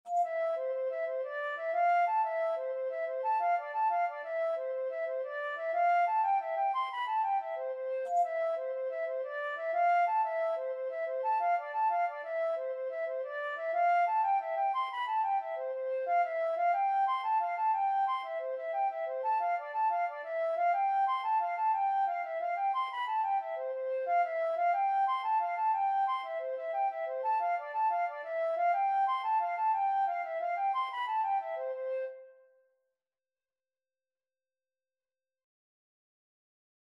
6/8 (View more 6/8 Music)
C6-C7
C major (Sounding Pitch) (View more C major Music for Flute )
Flute  (View more Intermediate Flute Music)
Traditional (View more Traditional Flute Music)